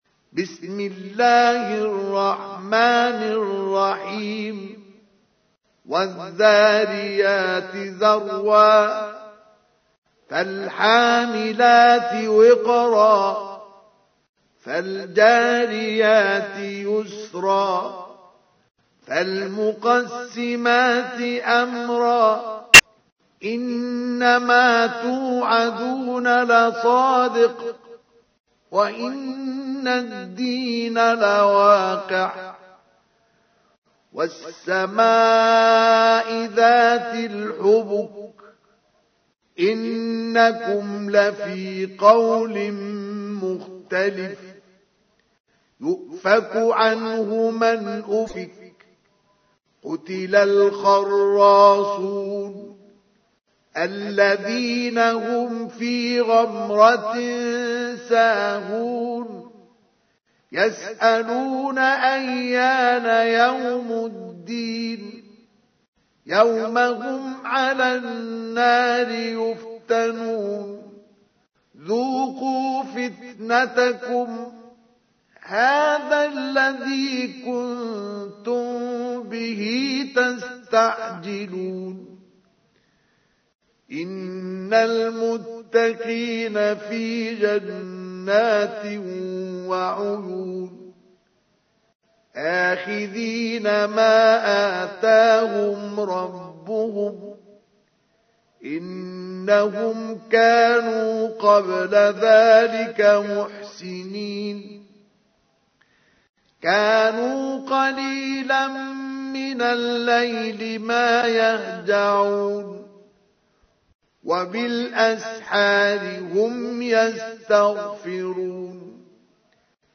تحميل : 51. سورة الذاريات / القارئ مصطفى اسماعيل / القرآن الكريم / موقع يا حسين